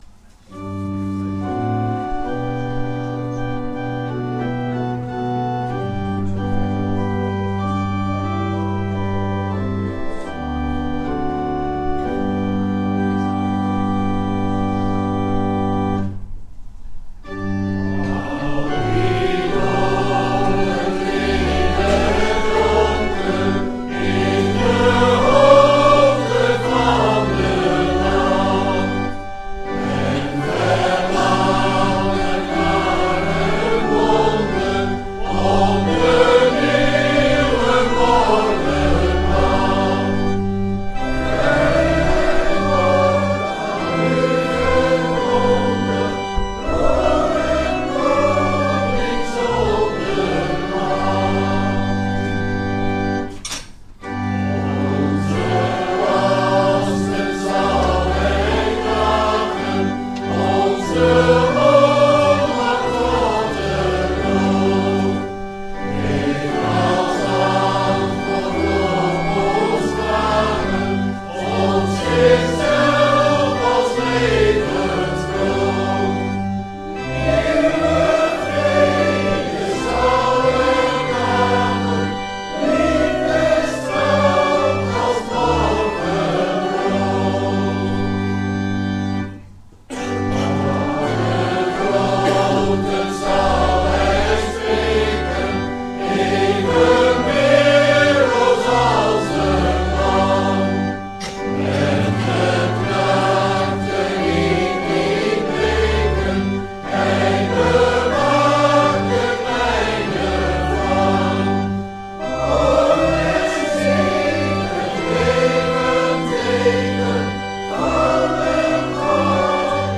Schriftlezing